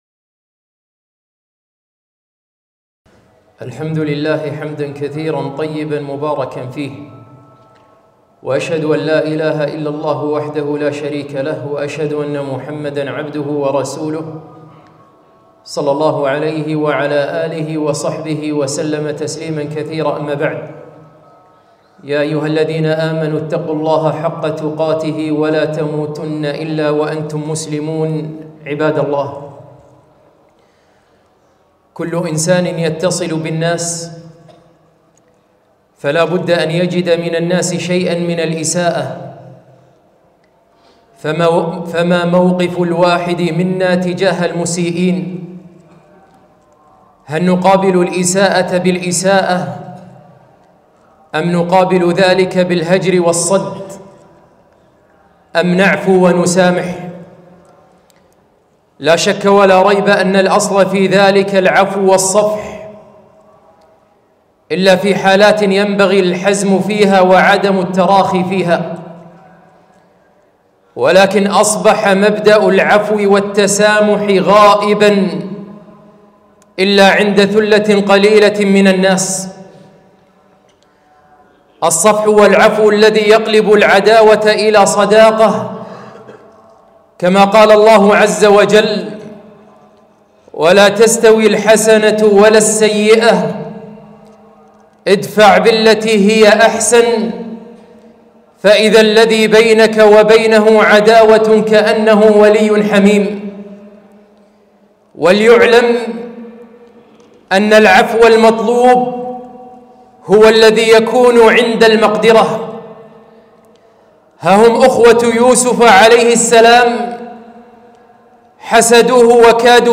خطبة - العفو والتسامح